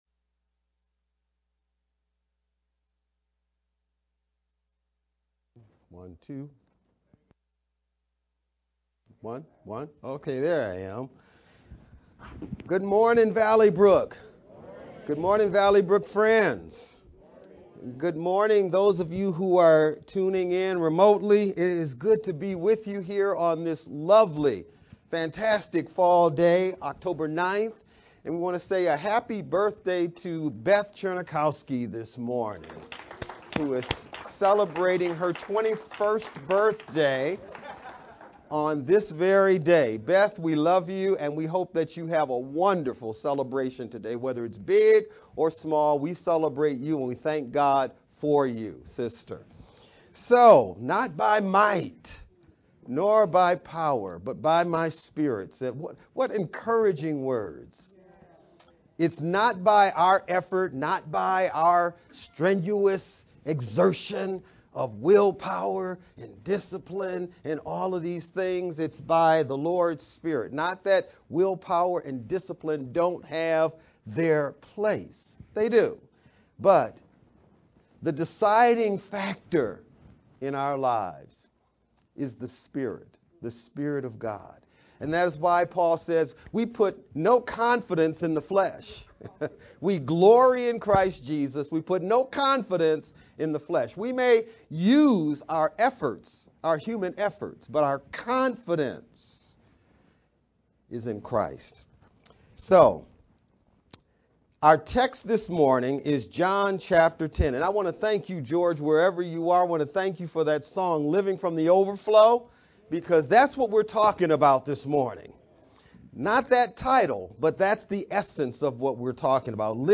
VBCC-Sermon-Oct.-9th-edited-sermon-only-CD.mp3